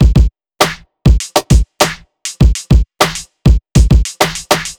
TI100BEAT2-R.wav